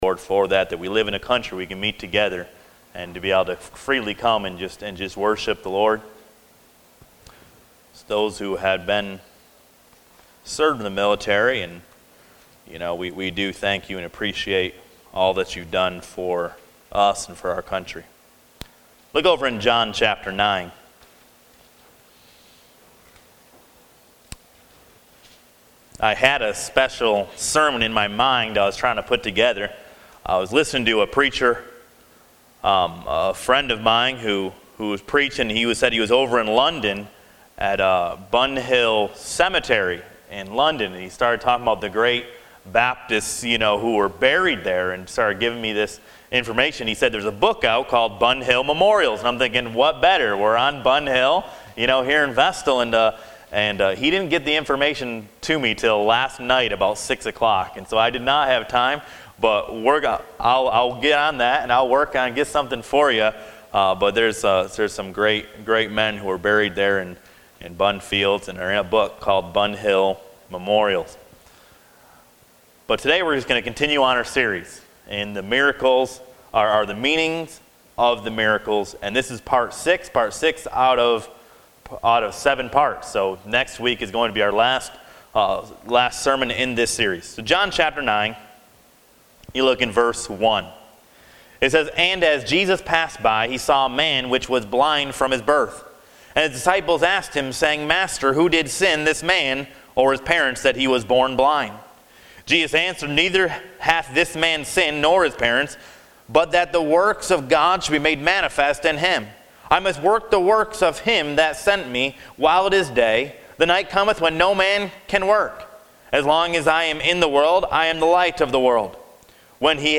According to John 9 Jesus stopped down and spit in the clay and annointed the eyes of the blind man and told him to wash in the pool of Siloam. Why did he heal him in this way? Listen to this sermon and find out the meaning of the Miracle.